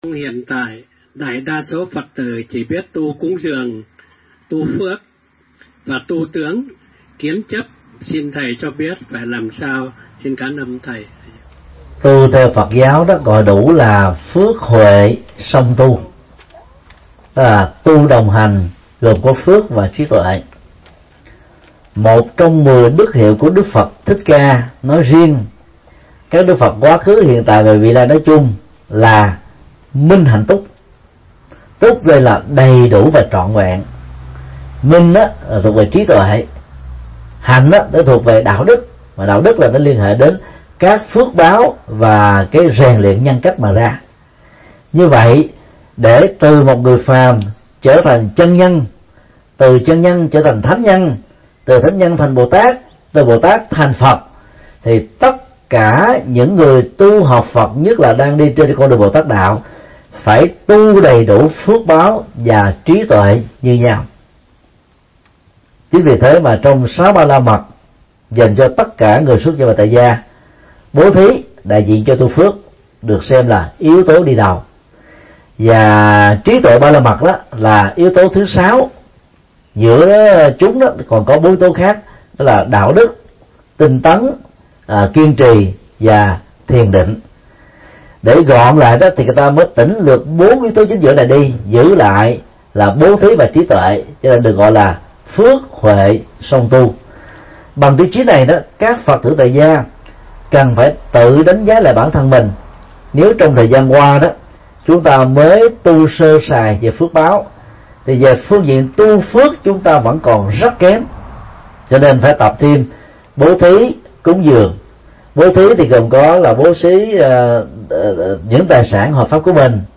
Vấn đáp: Phước huệ song tu, khóa tu Phật thất và Bát Quan Trai
Giảng tại đạo tràng Liên Tịnh, TP. Recke – Ibbenburen, Đức